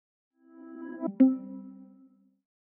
connection_recovered.wav